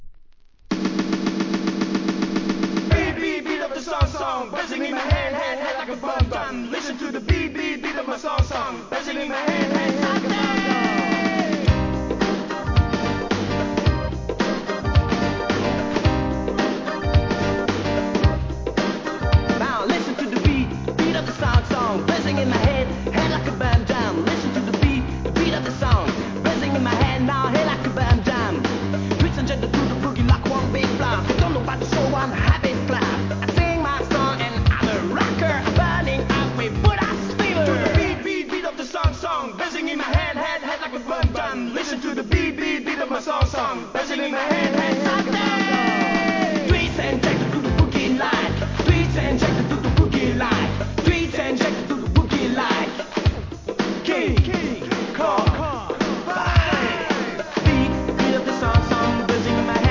HIP HOP/R&B
GO-GO調のトラックにOLD SCHOOLなRAP!!